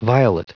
Prononciation du mot violet en anglais (fichier audio)
Prononciation du mot : violet